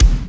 Bassdrums
ED Bassdrums 01.wav